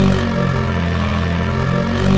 slave1_fly_loop.wav